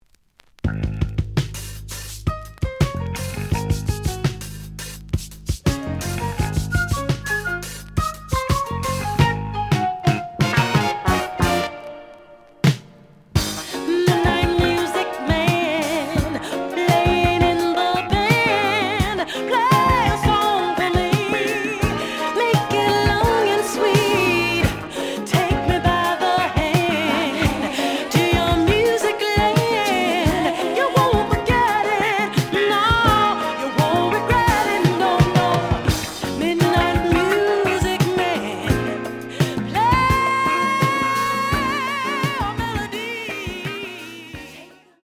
The audio sample is recorded from the actual item.
●Genre: Disco
Slight noise on beginning of both side, but almost good.)